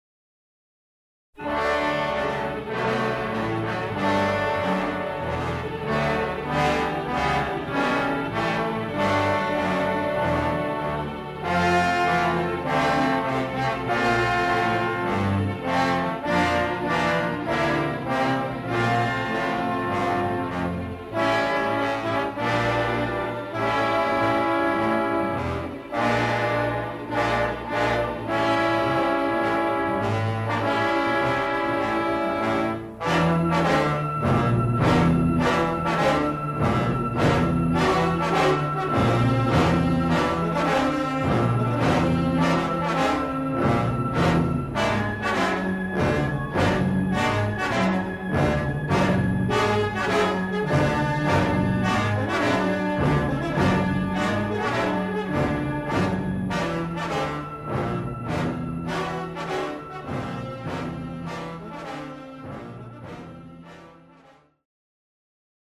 epic dramatic score